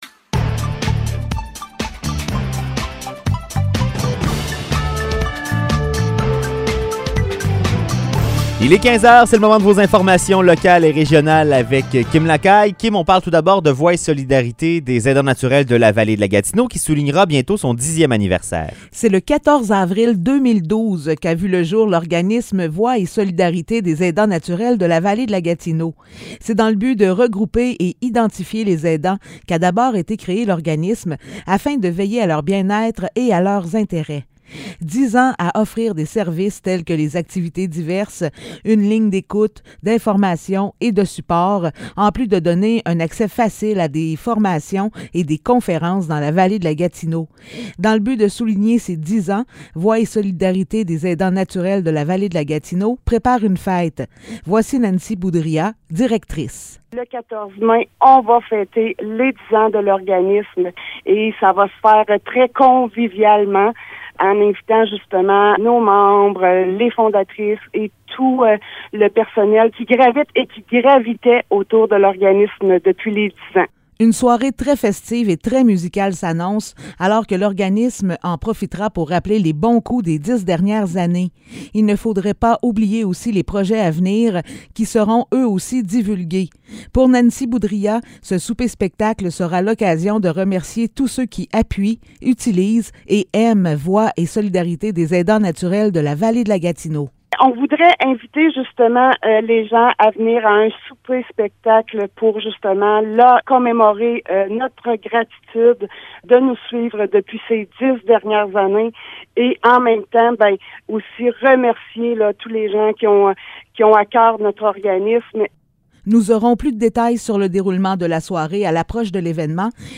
Nouvelles locales - 4 mars 2022 - 15 h